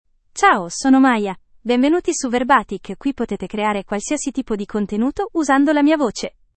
Maya — Female Italian (Italy) AI Voice | TTS, Voice Cloning & Video | Verbatik AI
Maya is a female AI voice for Italian (Italy).
Voice sample
Listen to Maya's female Italian voice.
Maya delivers clear pronunciation with authentic Italy Italian intonation, making your content sound professionally produced.